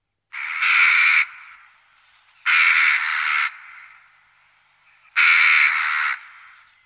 На этой странице собраны звуки галки – от характерных криков до пересвистов с сородичами.
Галка громко кричит